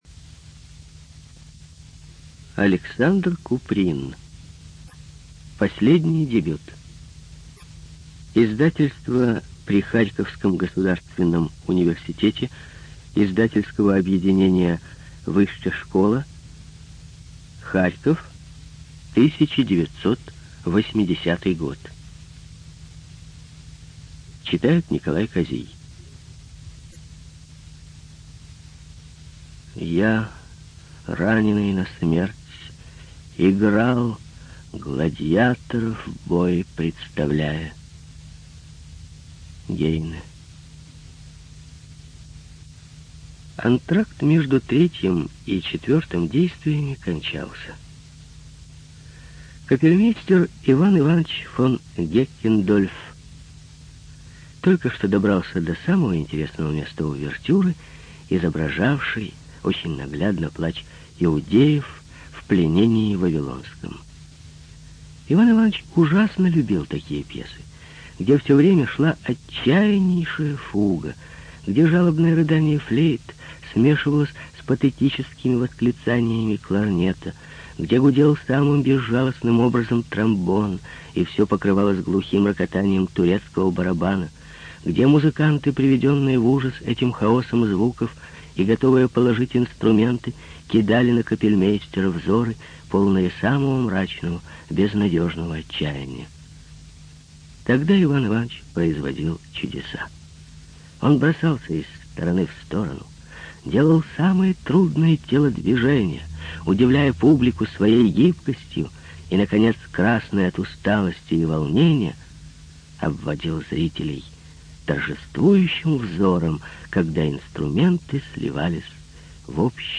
ЖанрКлассическая проза
Студия звукозаписиРеспубликанский дом звукозаписи и печати УТОС